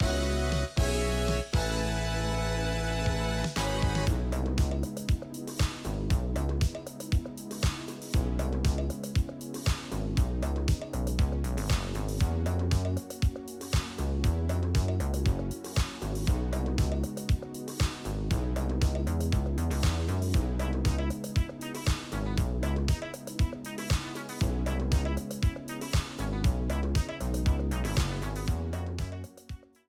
they are soundalikes of